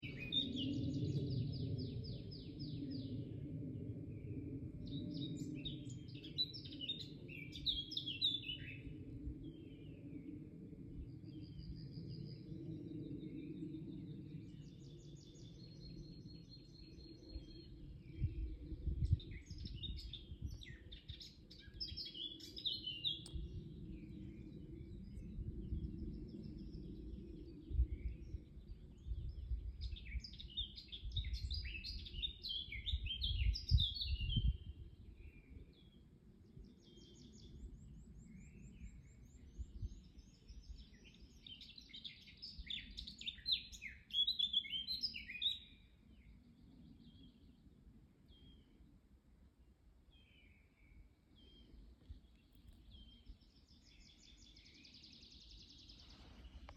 черноголовая славка, Sylvia atricapilla
Administratīvā teritorijaValkas novads
СтатусПоёт